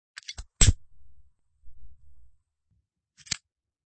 Descarga de Sonidos mp3 Gratis: encendedor 3.